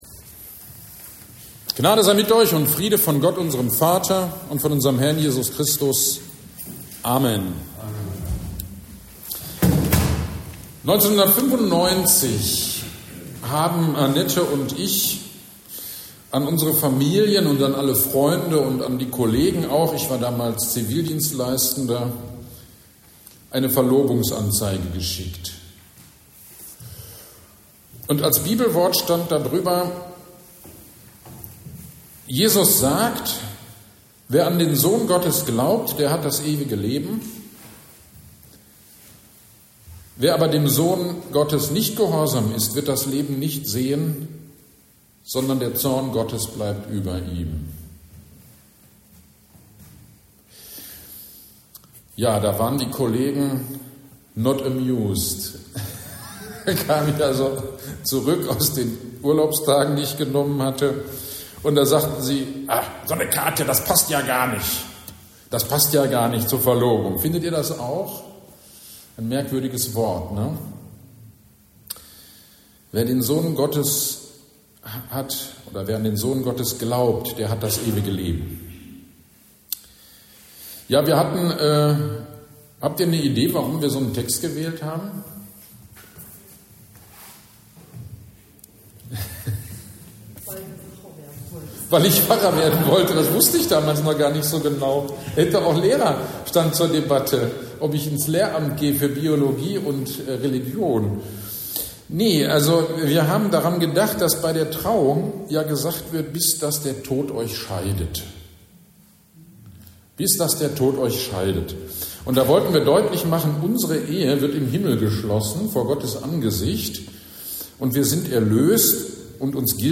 GD am 26.11.23 Predigt zu Johannes 5.24-29